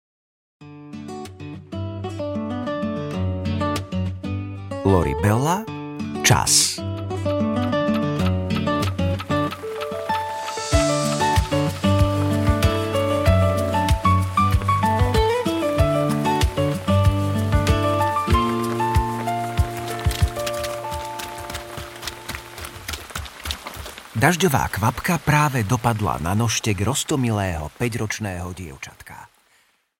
Pán Čas audiokniha
Ukázka z knihy
pan-cas-audiokniha